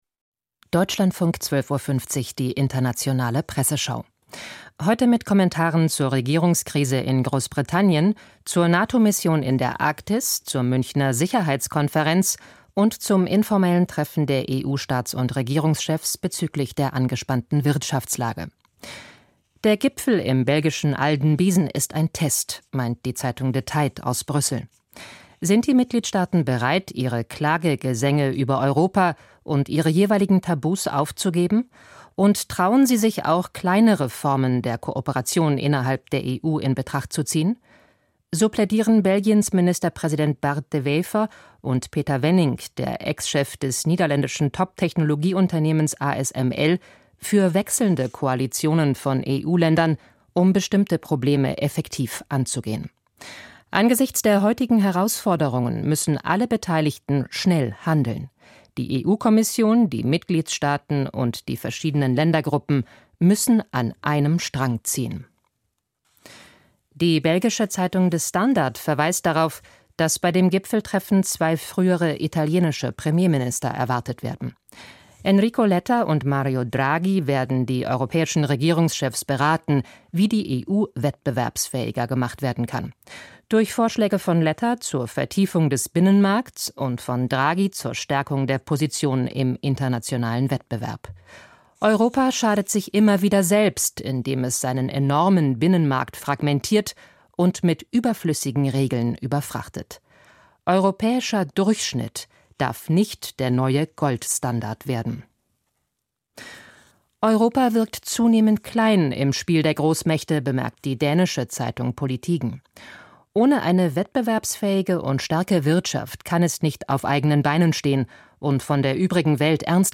Die internationale Presseschau